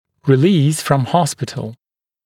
[rɪ’liːs frəm ‘hɔspɪtl][ри’ли:с фром ‘хоспитл]выписывать из больницы